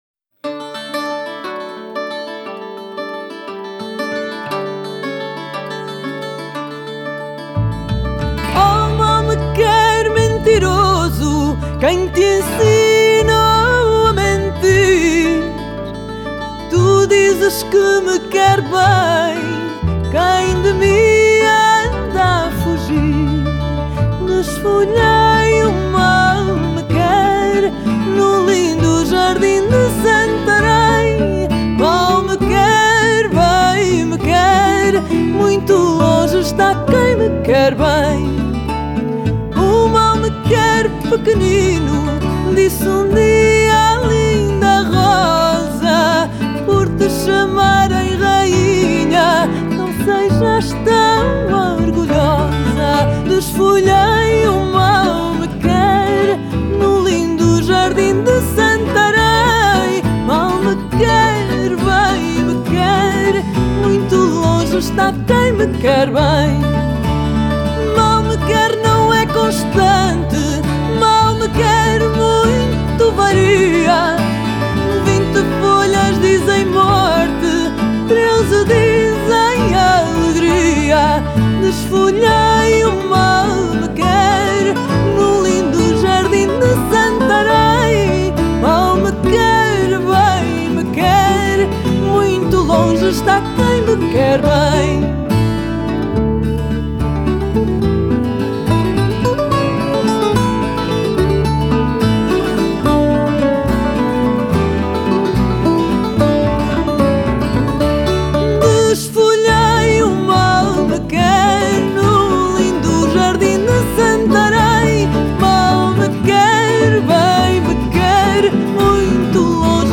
Genre: World, Fado